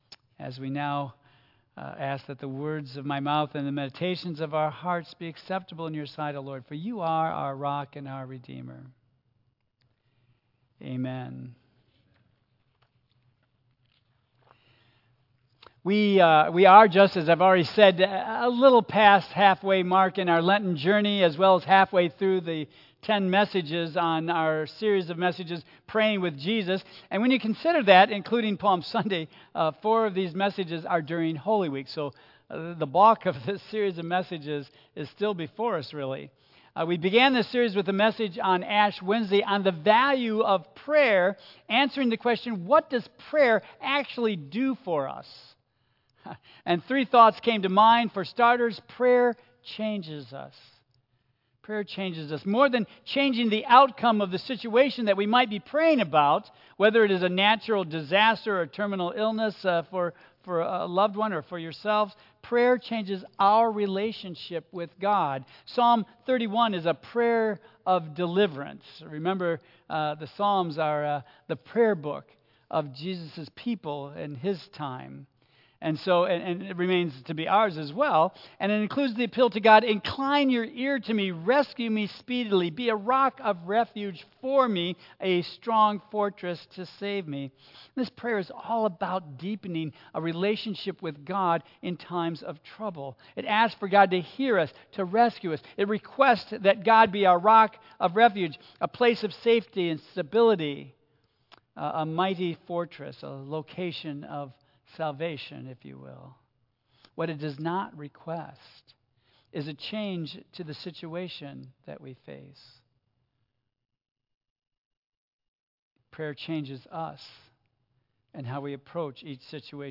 Tagged with Lent , Michigan , Sermon , Waterford Central United Methodist Church , Worship Audio (MP3) 9 MB Previous Praise God!